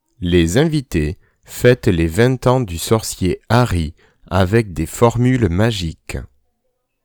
Les dictées du groupe C2 :